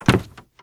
STEPS Wood, Creaky, Walk 10.wav